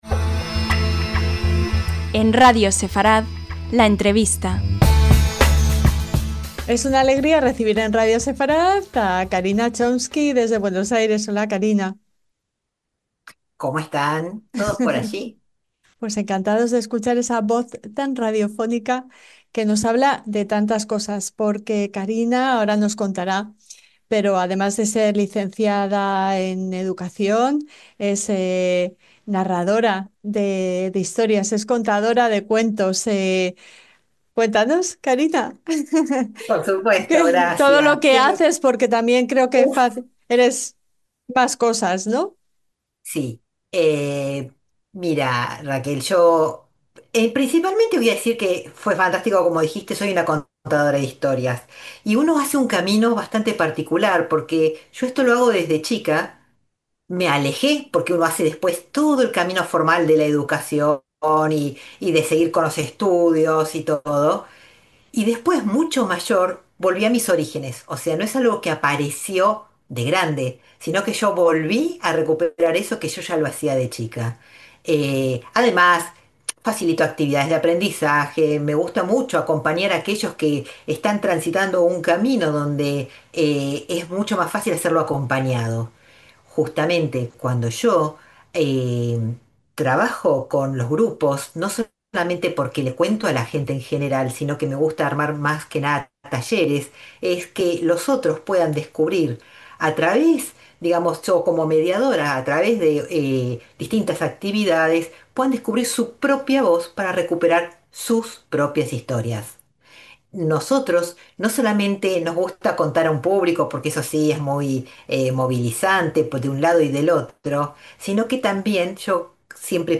LA ENTREVISTA-